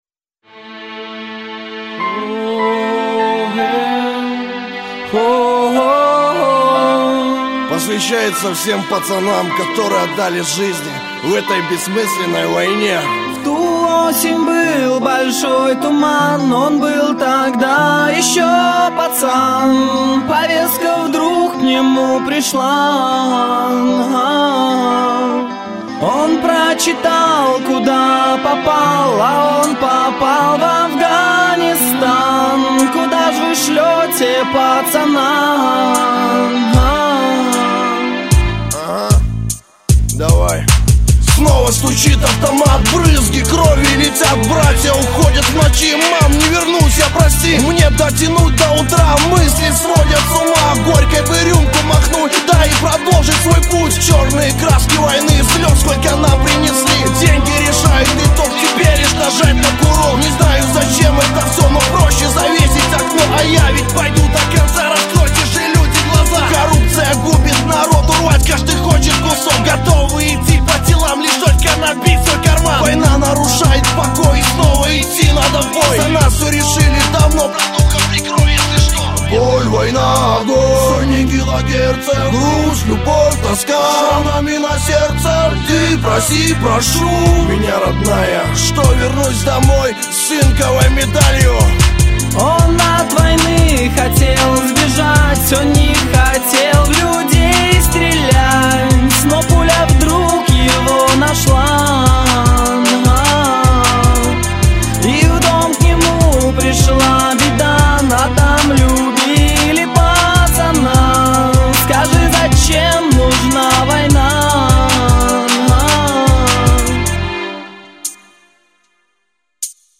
Категория: Hip-Hop - RAP